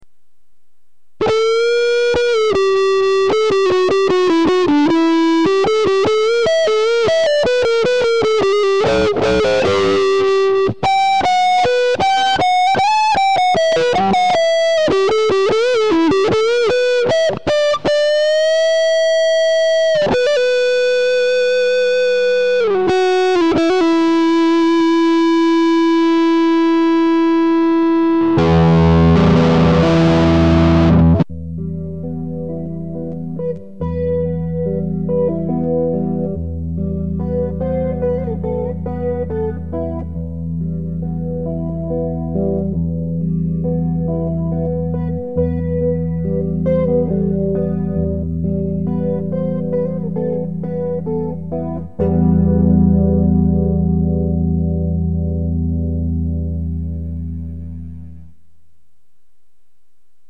Mozda je malkice preglasno...